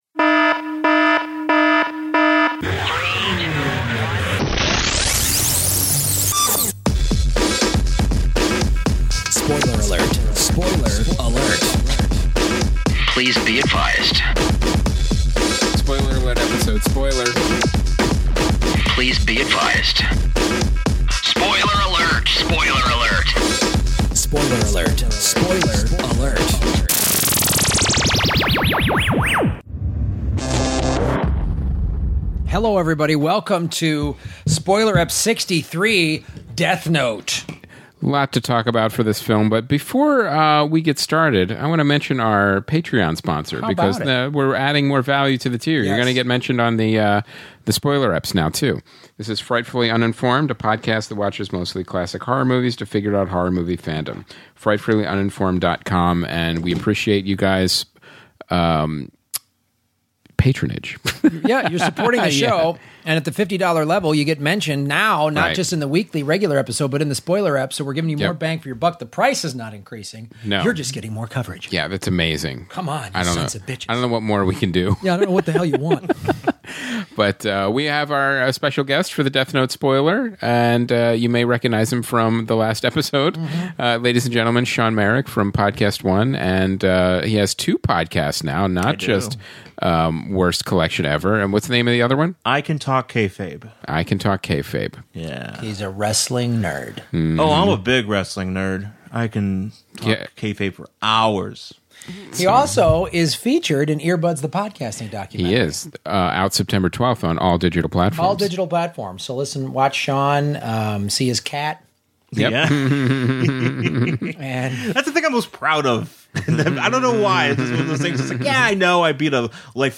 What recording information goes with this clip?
in the garage to talk about al the problems with this film.